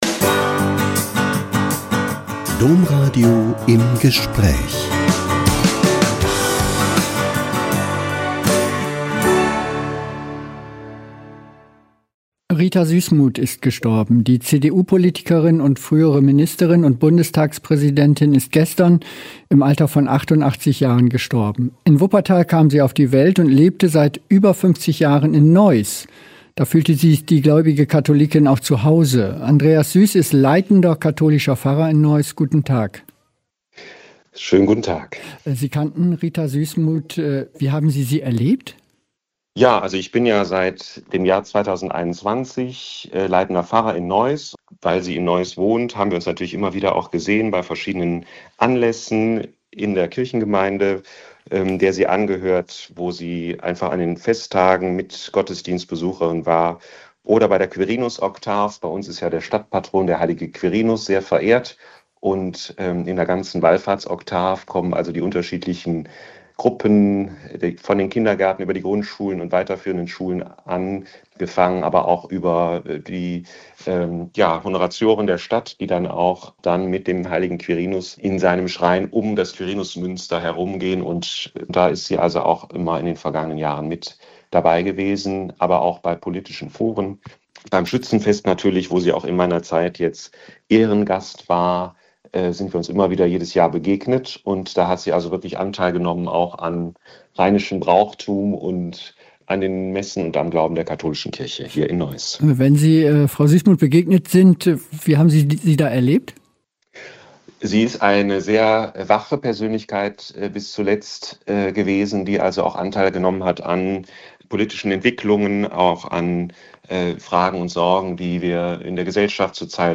Interview erzählt er, wie Rita Süssmuth sich vom Glauben getragen